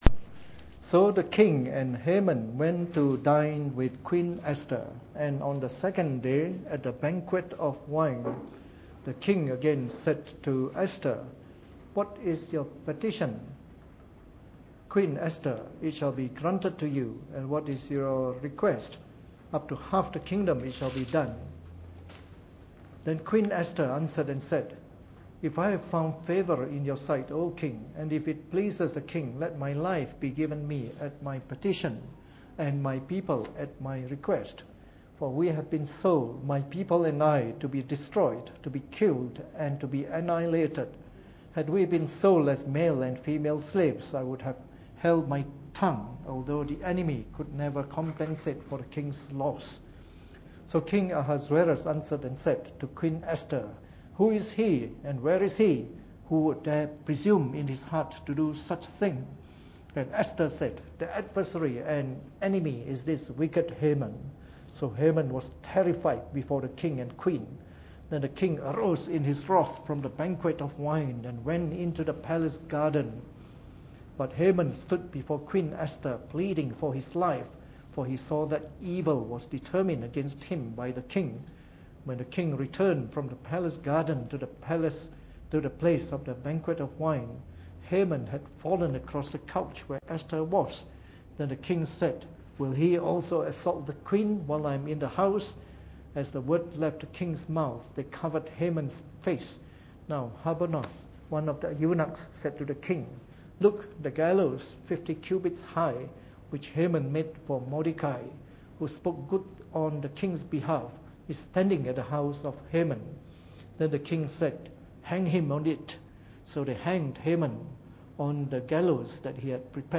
Preached on the 13th of November 2013 during the Bible Study, from our series of talks on the Book of Esther.